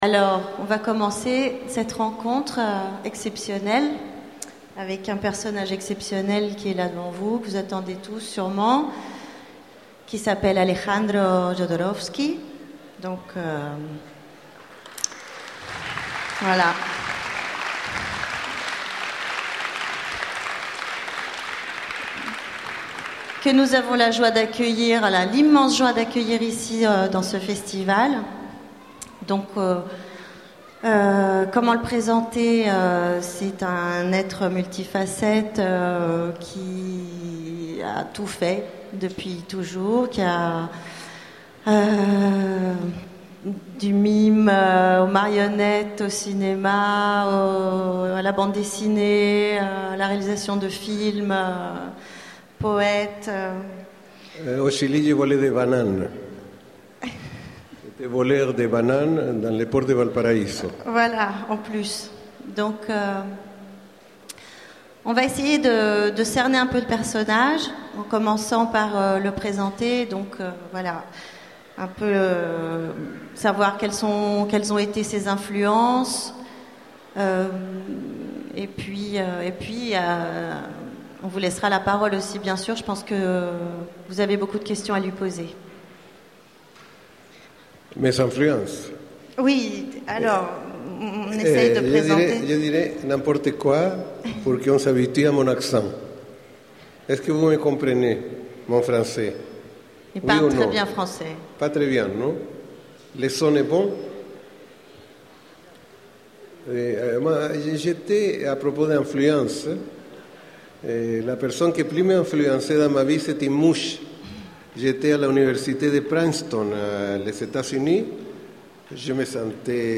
Utopiales 2011 : Conférence Rencontre avec Alejandro Jodorowsky
Utopiales 2011 : Conférence Rencontre avec Alejandro Jodorowsky Voici l'enregistrement de la rencontre avec Alejandro Jodorowski. Télécharger le MP3 Genres / Mots-clés Rencontre avec un auteur Conférence Partager cet article